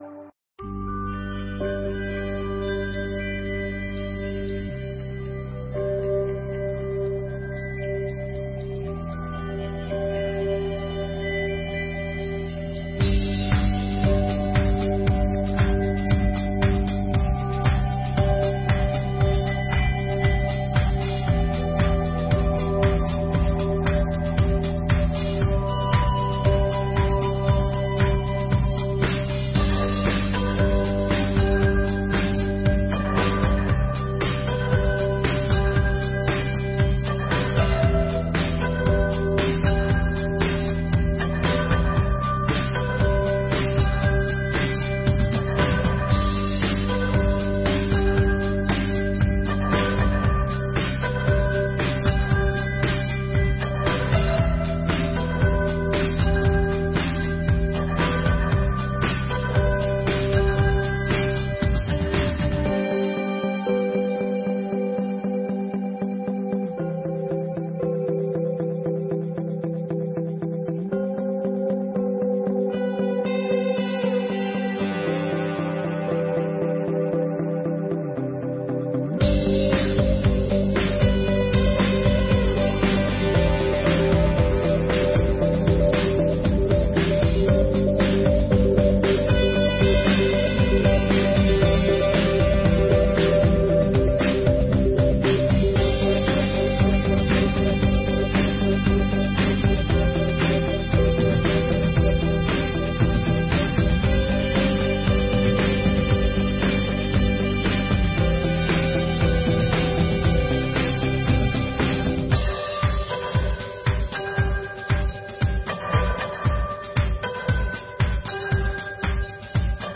9ª Sessão Ordinária de 2024 — Câmara Municipal de Garça